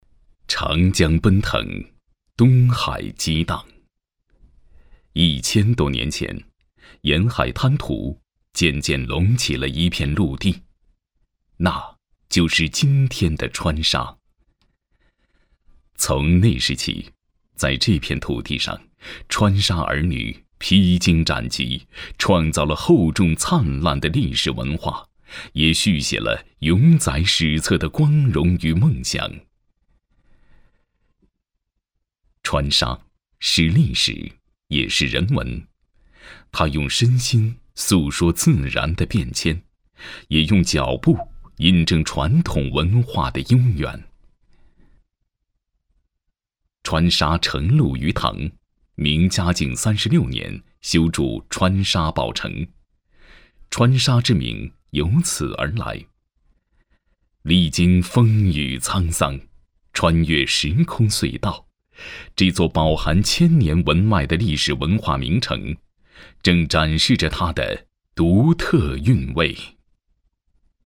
男国213_宣传片_旅游_川沙_浑厚.mp3